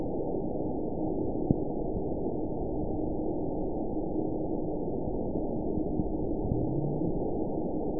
event 921959 date 12/23/24 time 08:59:07 GMT (5 months, 4 weeks ago) score 9.14 location TSS-AB04 detected by nrw target species NRW annotations +NRW Spectrogram: Frequency (kHz) vs. Time (s) audio not available .wav